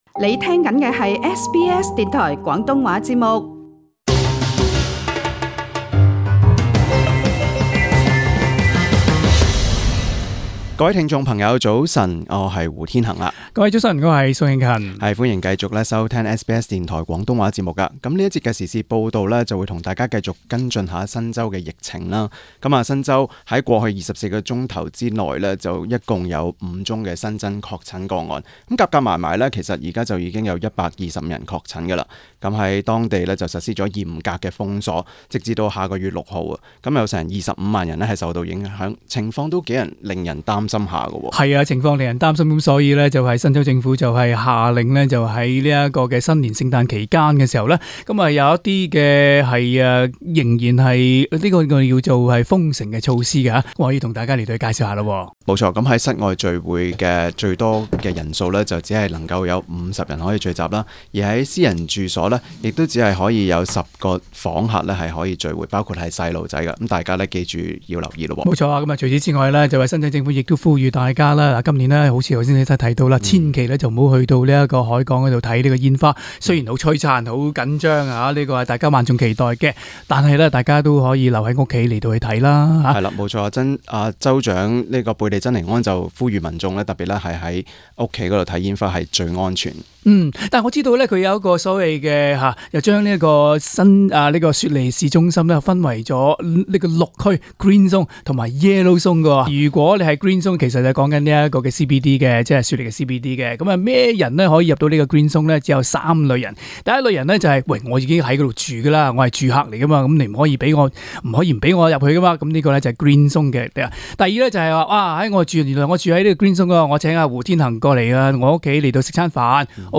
今天的【時事報道】繼續跟進新州疫情及除夕倒數的限制措施，而聯邦衛生部長亨特（Greg Hunt）表示，澳洲的新冠疫苗接種計劃可望提早在明年十月或之前完成。